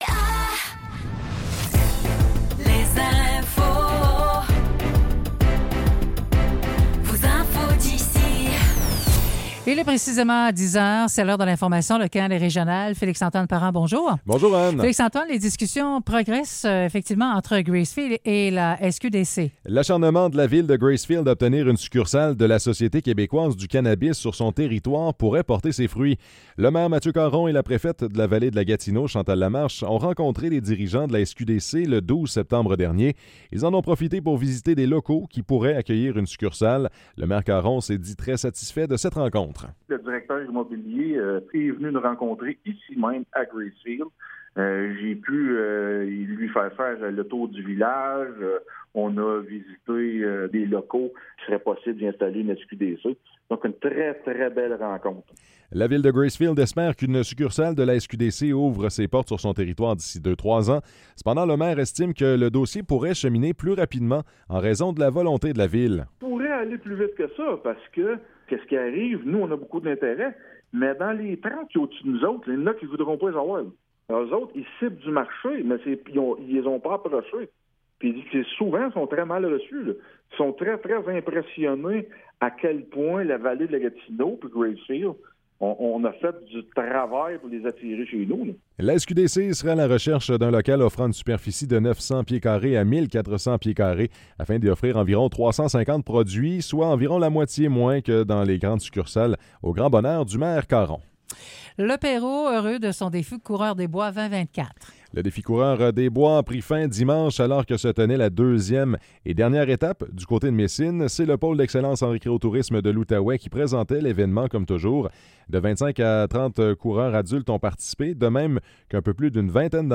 Nouvelles locales - 1er octobre 2024 - 10 h